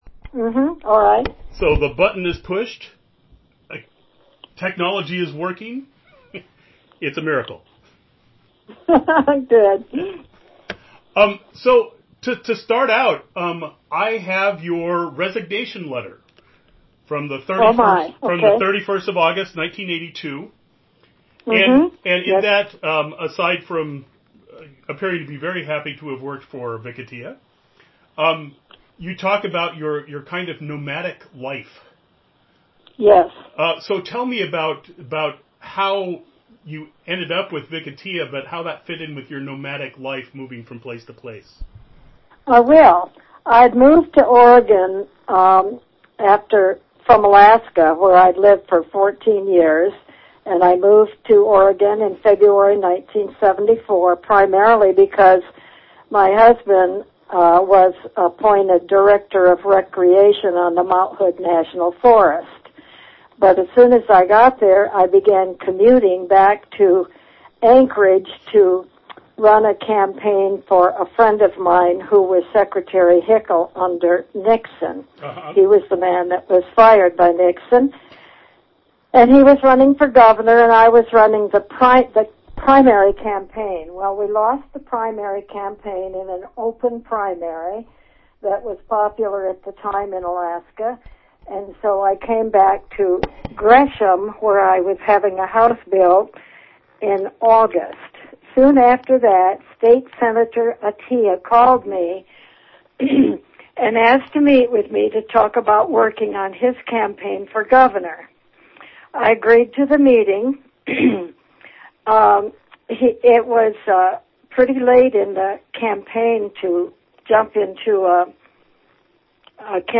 Description An interview